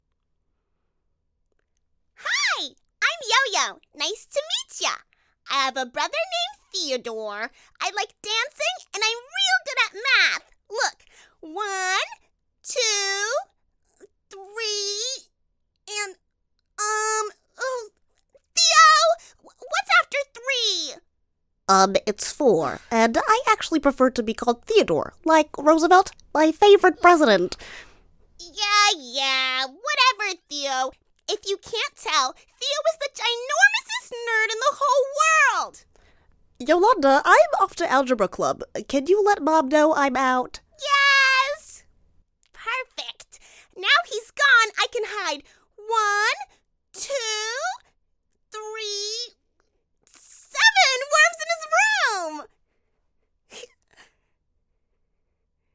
Cool and assured American voice with influence.
Animation